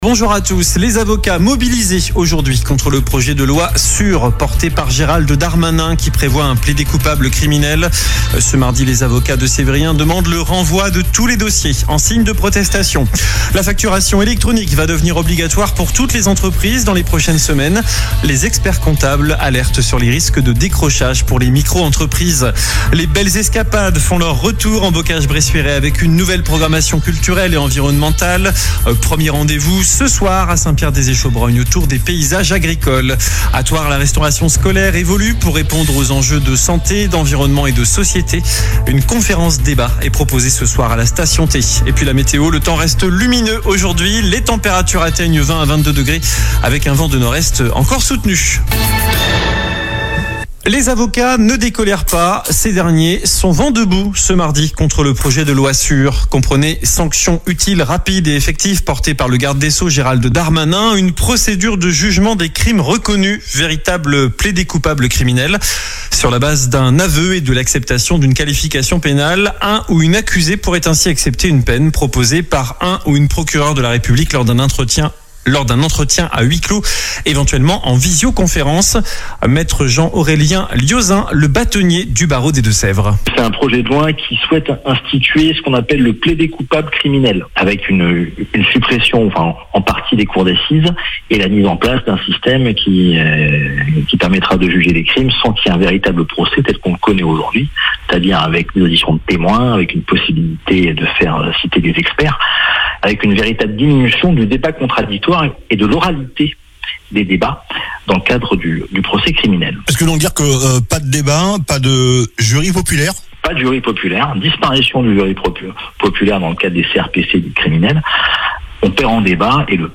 Journal du mardi 21 avril (midi)